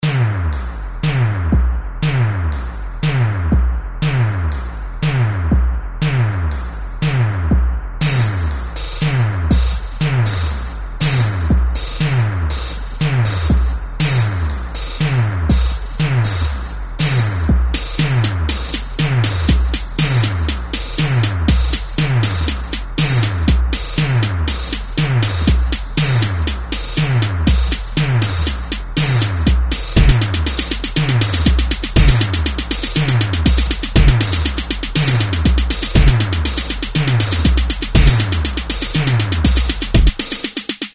适合任何风格的音乐。
标签： 120 bpm Drum And Bass Loops Drum Loops 3.67 MB wav Key : Unknown
声道单声道